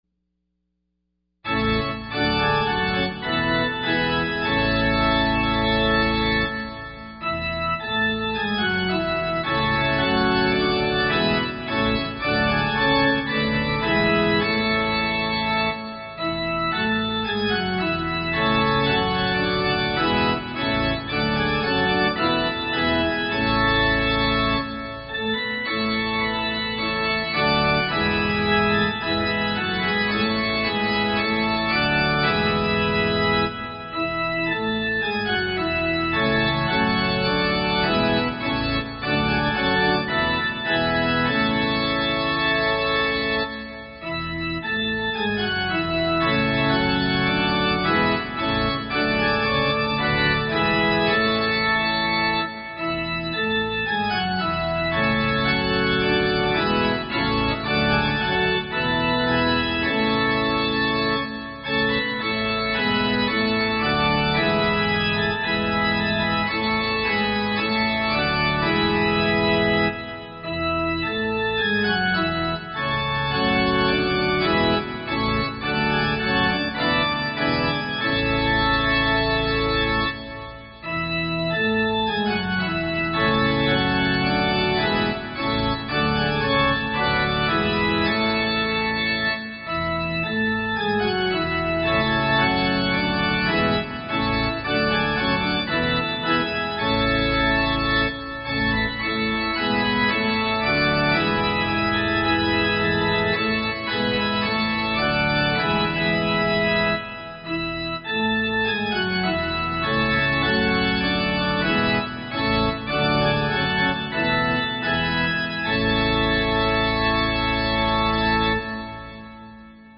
Easter Morning Hymns